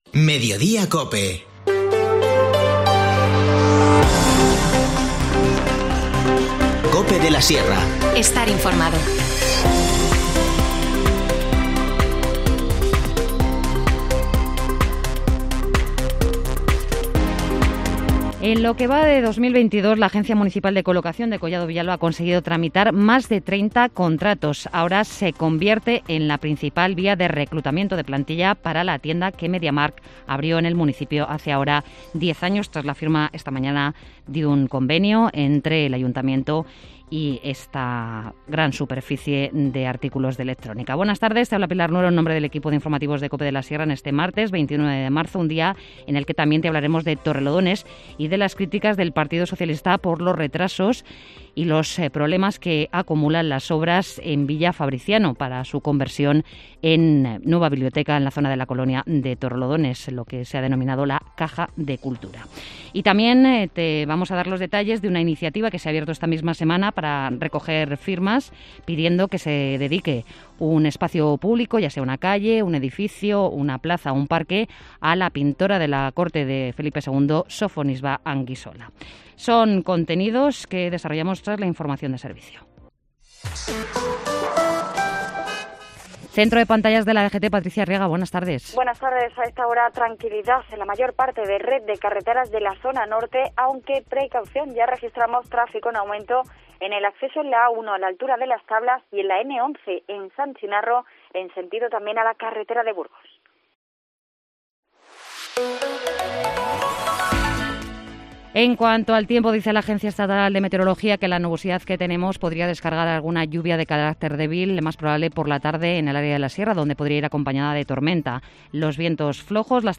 Informativo Mediodía 29 marzo